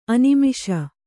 ♪ animiṣa